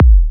Psytrance Kick Drums " Kick 23 Minimal
描述：短促的Psytrance/Progressive/Minimal kick
Tag: 最小的 渐进的 psytrance